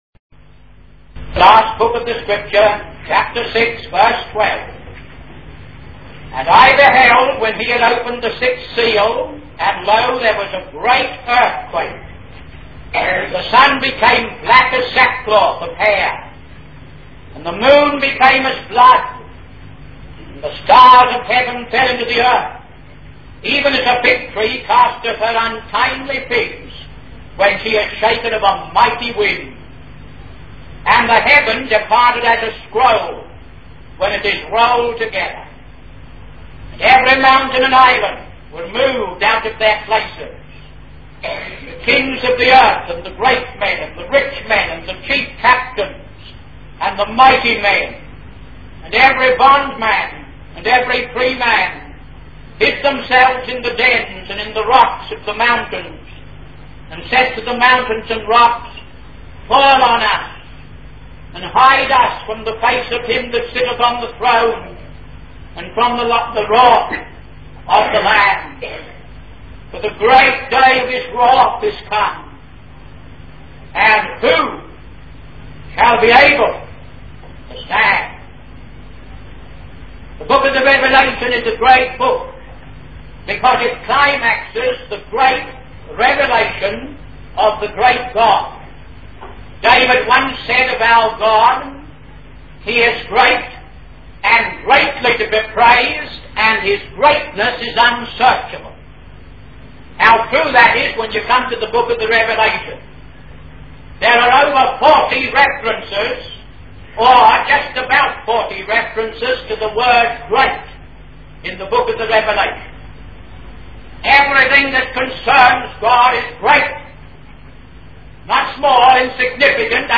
The sermon culminates in a call to recognize the Lamb's sacrifice and the hope it brings for those who trust in Him.